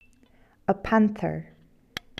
animal6 panther
animal6-panther.mp3